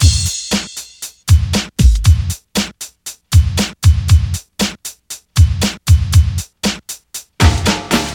• 117 Bpm Modern Hip-Hop Breakbeat Sample E Key.wav
Free breakbeat - kick tuned to the E note. Loudest frequency: 1637Hz
117-bpm-modern-hip-hop-breakbeat-sample-e-key-4JL.wav